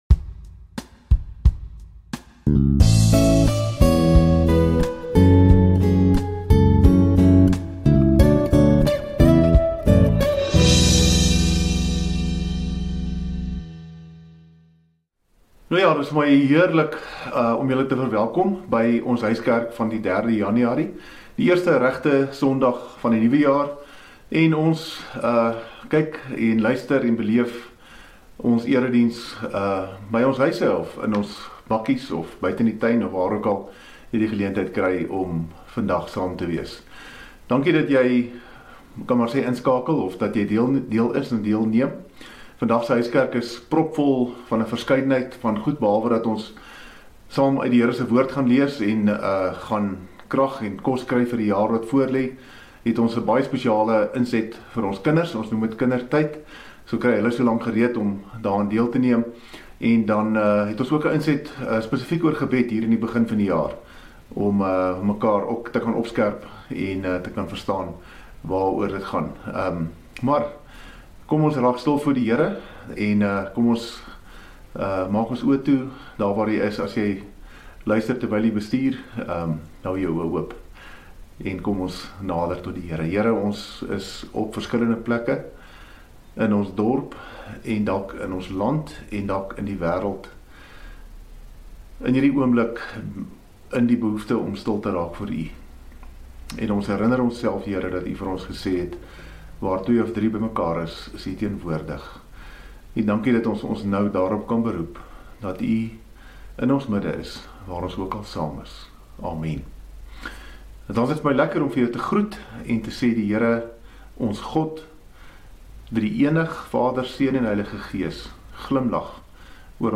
Daar is lof en aanbiddingsliedere, ‘n spesiale eerste uitgawe van “Kindertyd” vir ons jongspan en ook ‘n inset oor gebed en belangrike inligting oor die pad vorentoe in ons gemeente se onmiddellike toekoms.